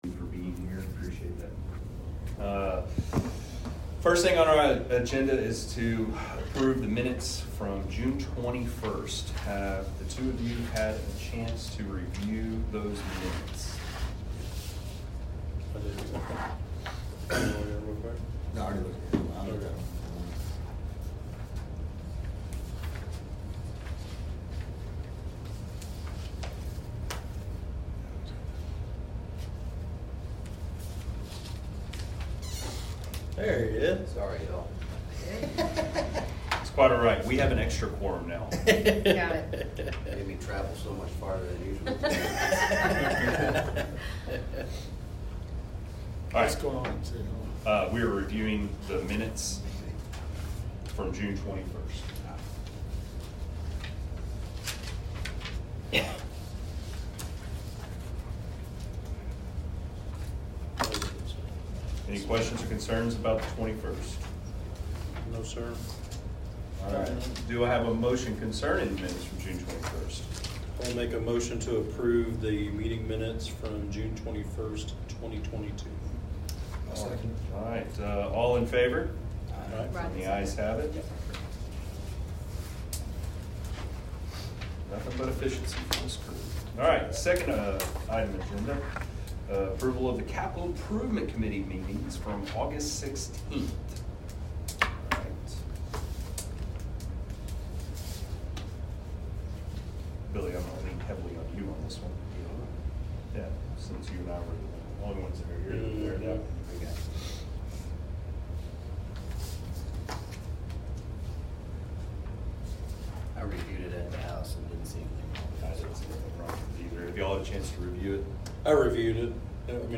Location and Time: Change of Location: Willow Park Public Safety Building, 101 Stagecoach Trail, Willow Park, TX 76087, 6PM